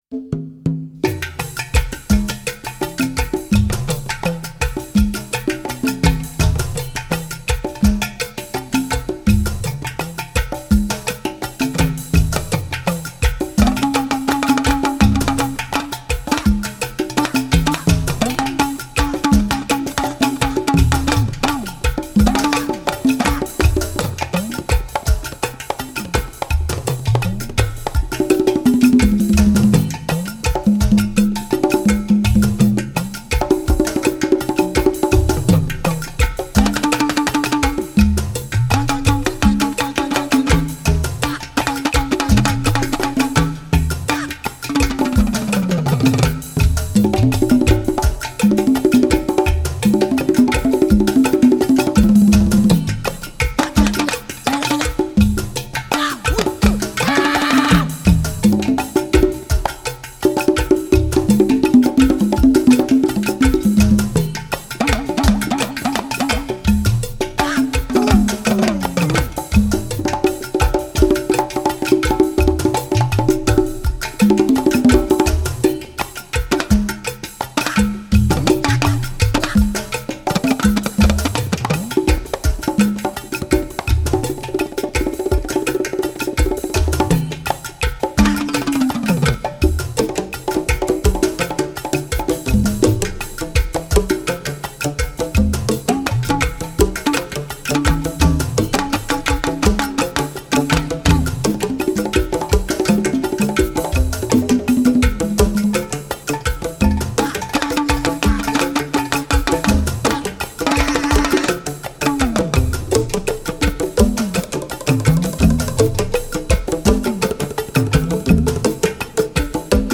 アフリカとアラビアが混じり合った見事なパーカッション・アンサンブル！！